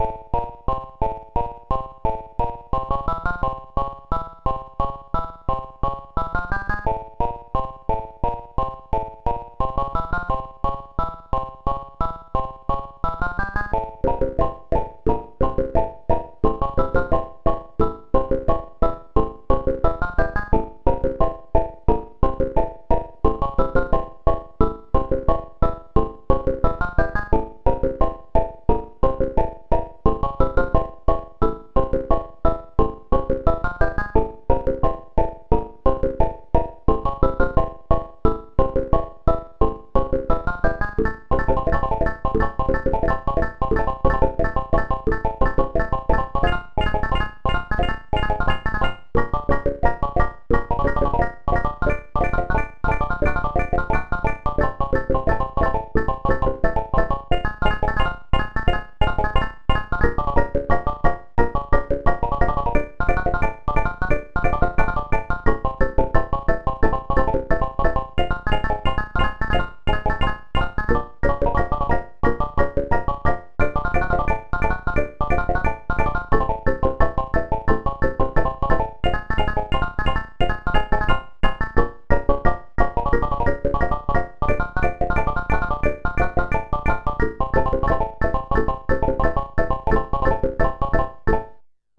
It can do microtonal synthesis using 8 oscillators, each having 32 8-bit waveforms to choose from, and 4 very rough amplitude envelope generators. It is played using a sequencer made up of 4 seperate algorithms each controlling a voice consisting of 2 oscillators and an amplitude envelope generator. Pitches can either be defined using ratios or cents for microtonal synthesis and the sequencing algorithms are completely independent of each other for multitemporal sequencing.
knyst_synthesizer_01.mp3